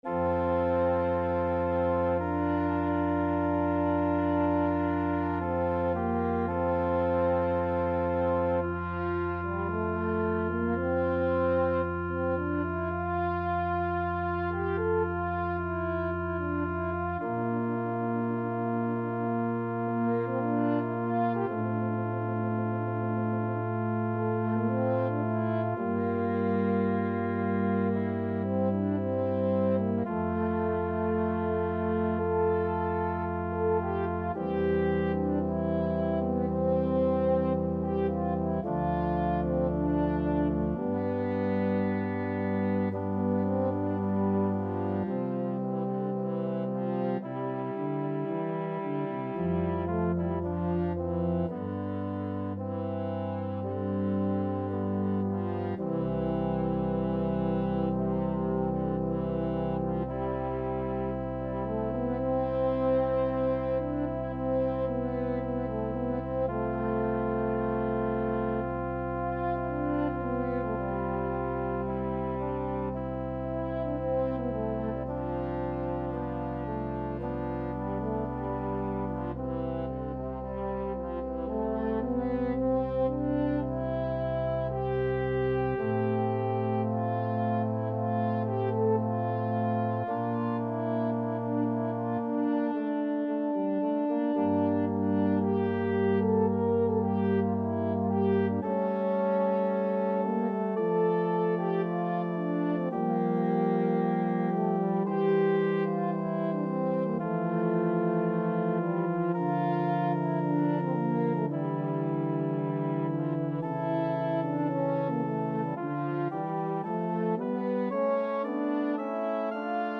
4/4 (View more 4/4 Music)
=56 Adagio
Classical (View more Classical French Horn Music)